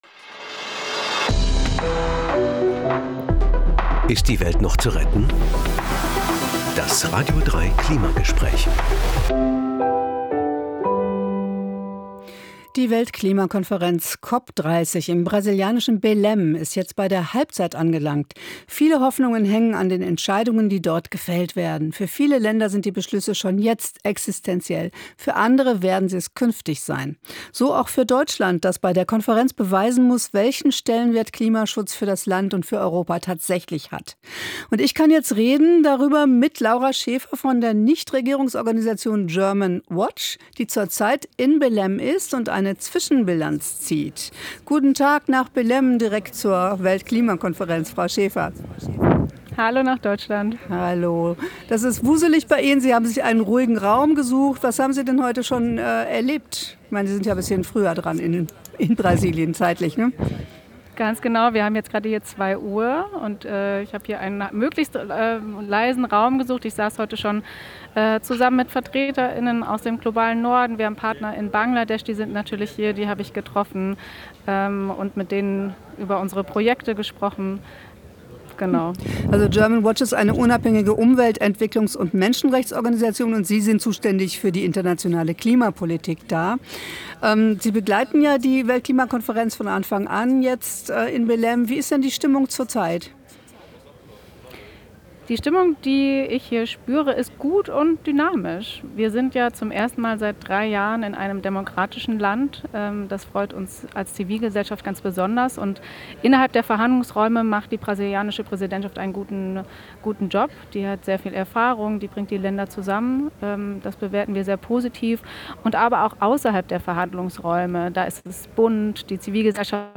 Klimagespräch